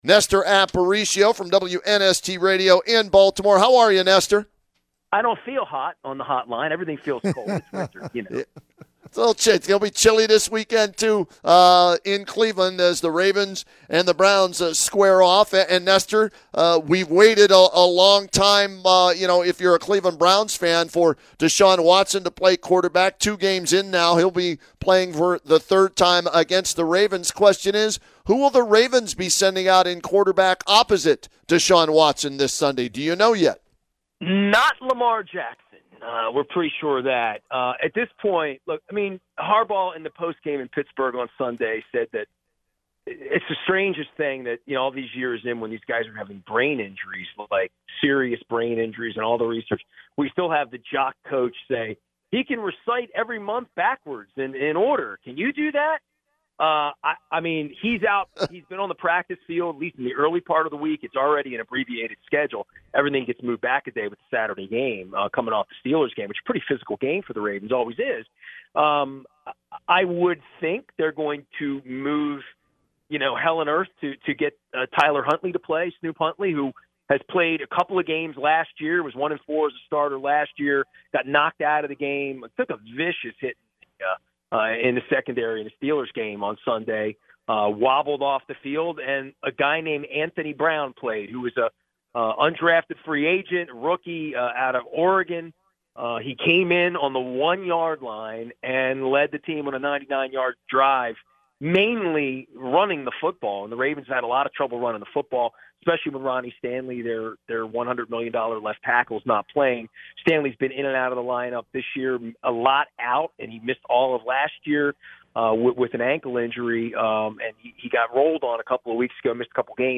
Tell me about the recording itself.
via Ohio radio WNBC in Canton